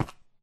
Sound / Minecraft / step / stone3.ogg
stone3.ogg